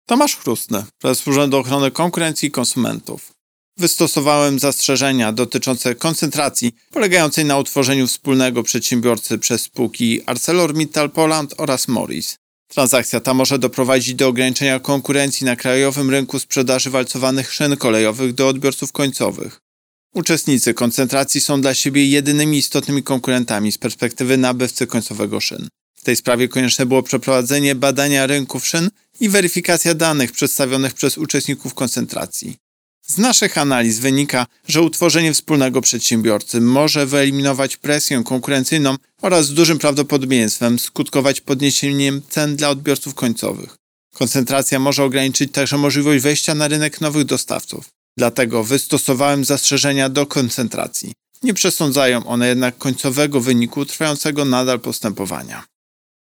Pobierz wypowiedź Prezesa UOKiK Tomasza Chróstnego W tej sprawie konieczne było przeprowadzenie badania rynków szyn i weryfikacja danych przedstawionych przez uczestników koncentracji.